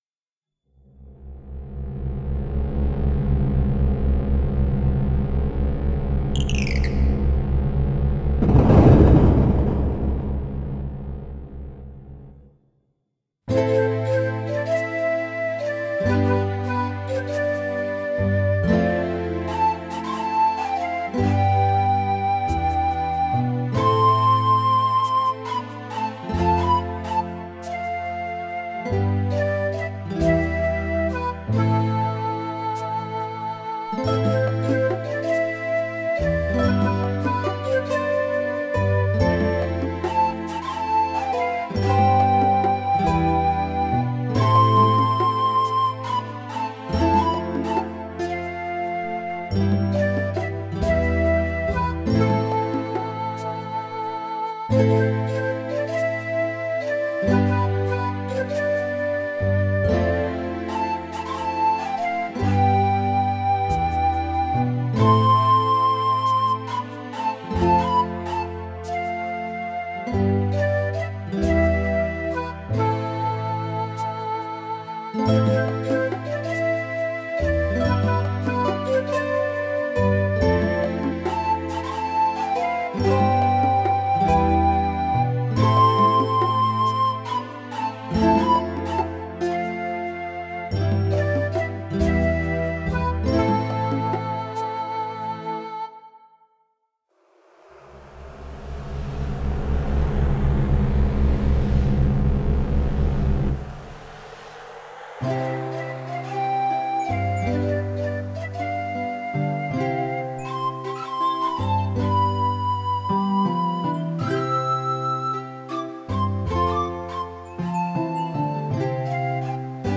Recorded digitally, using a M-Audio FireWire Audiophile.